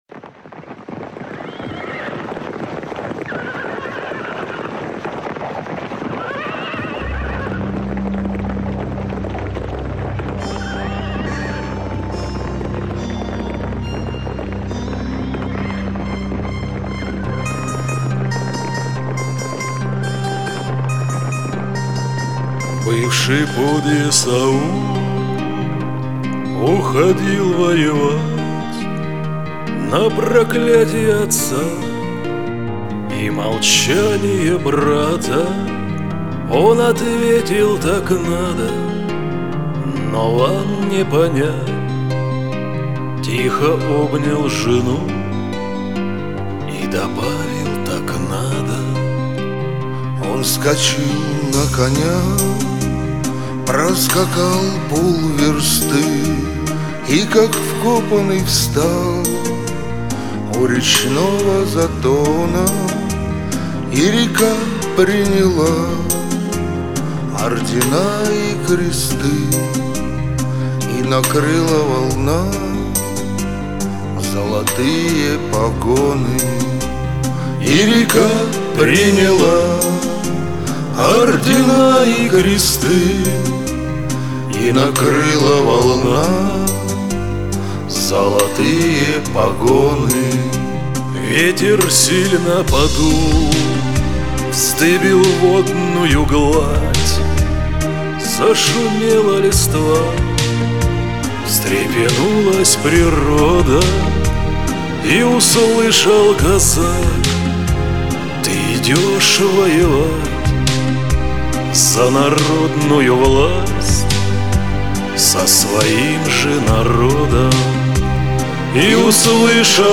А вот то, что все трое в один микрофон - это кайф!!!!!!!